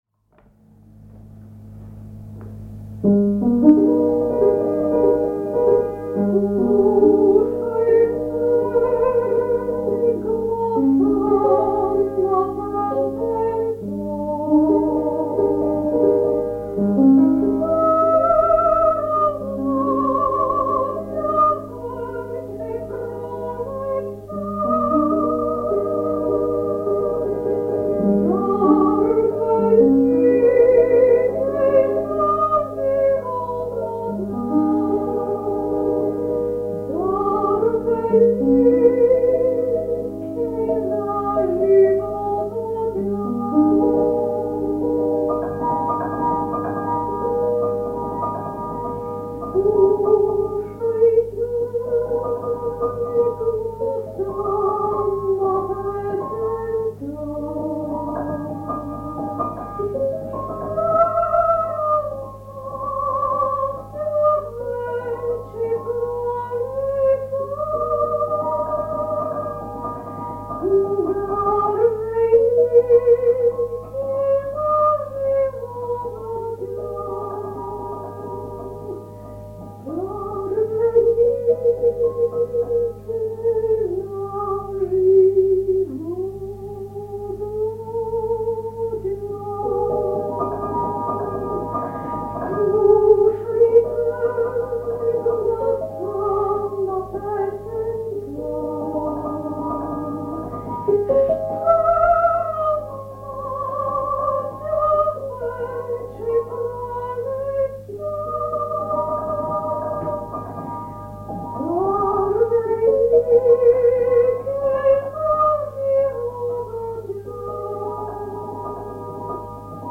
Prolet – Primavera Spartito non ancora disponibile Versione strumentale Prolet Prolet Versione cantata Prolet Prolet Prolet Testo traslitterato Prolet Slušajte glasa na pesenta, što raznasja veče proletta.